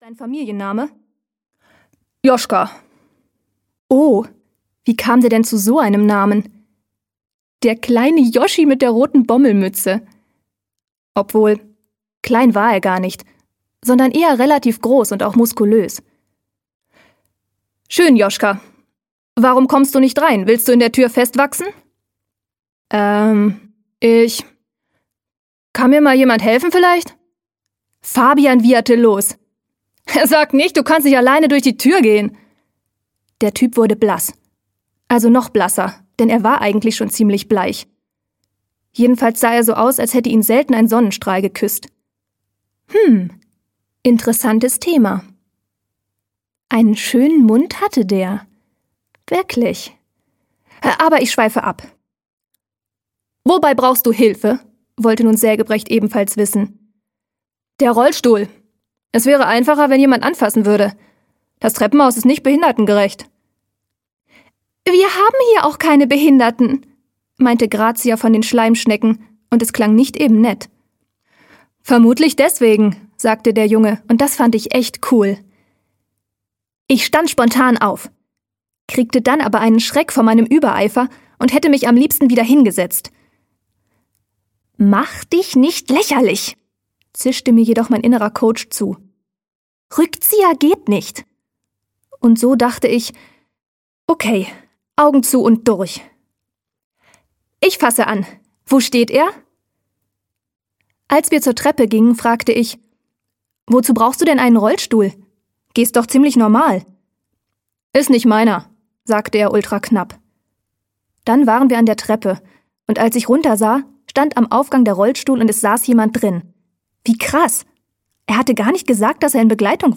Freche Mädchen: Der Neue & andere Katastrophen - Bianka Minte-König - Hörbuch